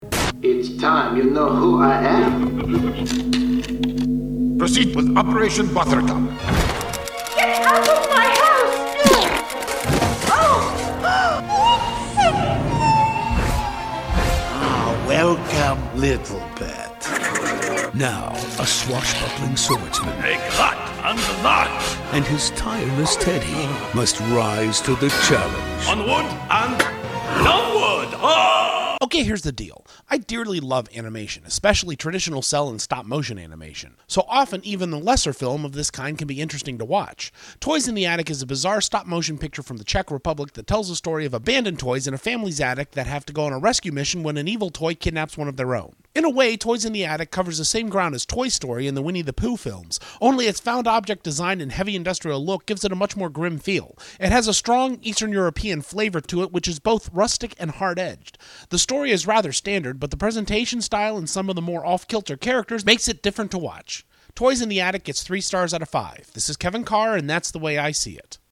Movie Review: ‘Toys in the Attic’